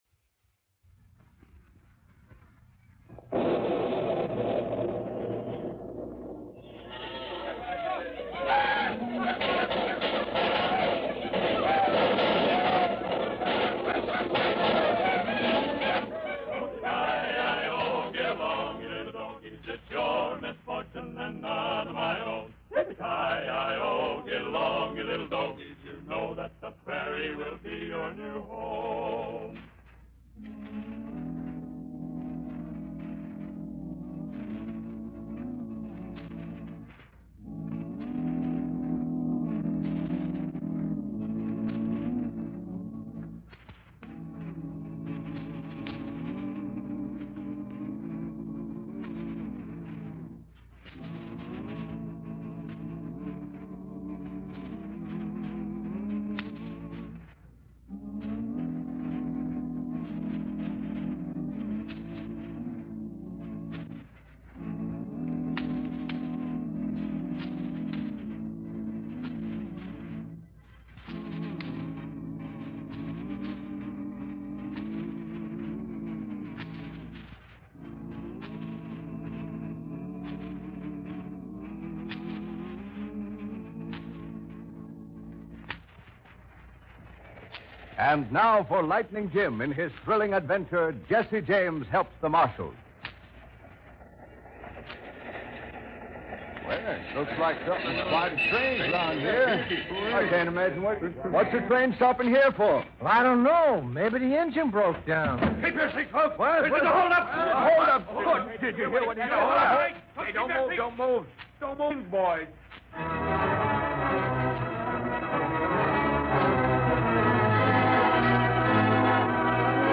"Lightning Jim" was a popular old-time radio show that featured the adventures of U.S. Marshal Lightning Jim Whipple. - The character of Lightning Jim, along with his trusty horse Thunder and deputy Whitey Larson, became iconic figures in Western radio drama. - The show is known for its portrayal of Western themes and characters, including stereotypical portrayals of Native Americans and historical events like the development of the Union-Pacific Railroad.